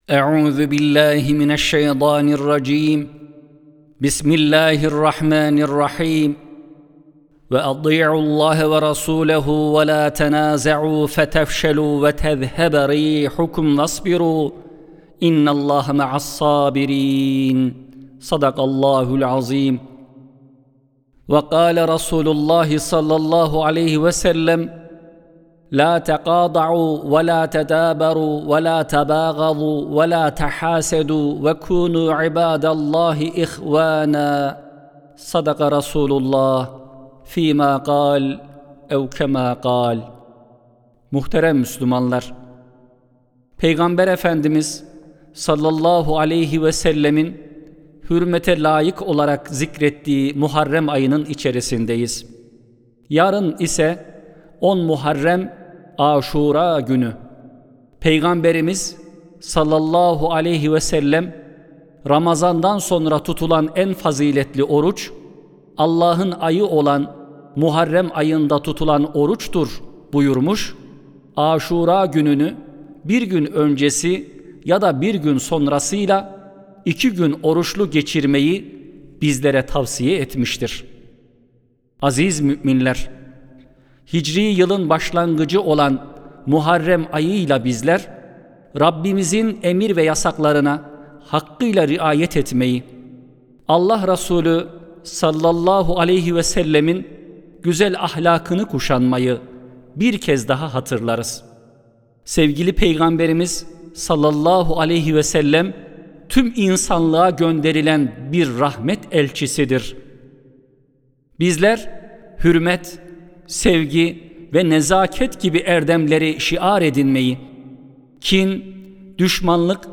Sesli Hutbe (Geçmişe İbretle Geleceğe Ferasetle Bakalım).mp3